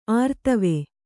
♪ ārtave